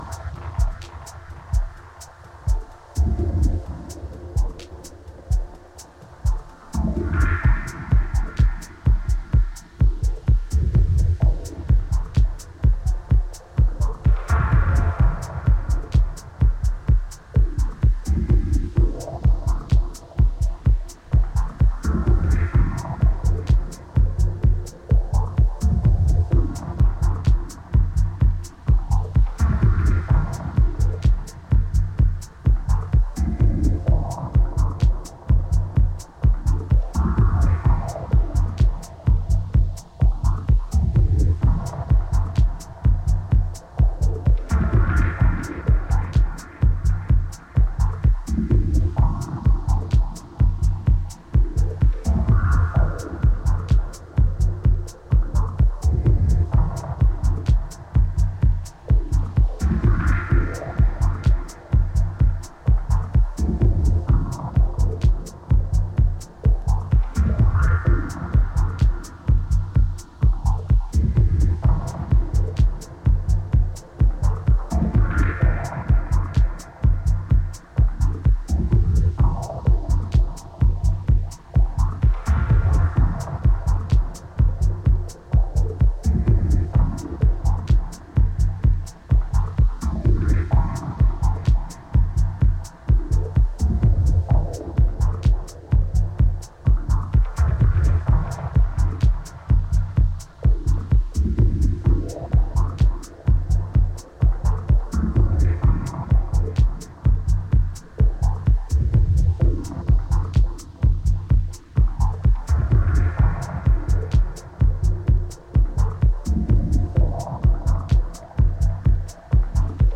New Release Dub Techno Techno